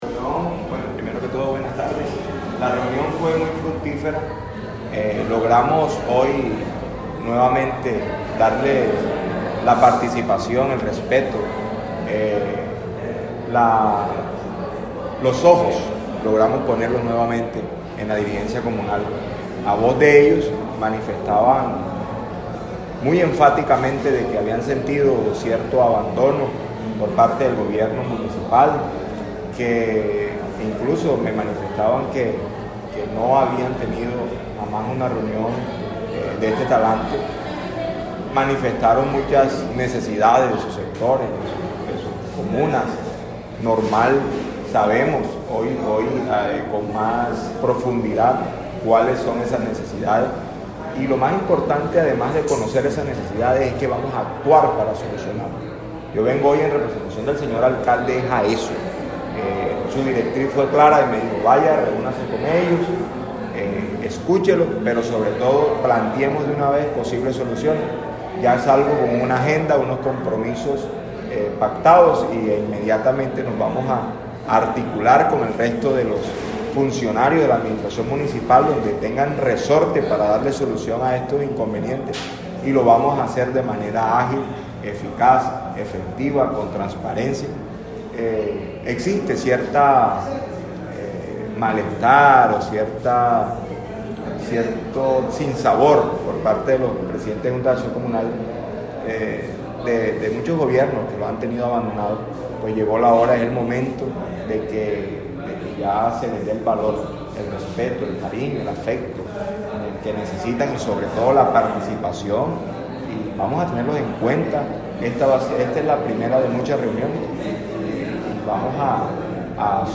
Hector-Zuleta-Secretario-de-Gobierno.mp3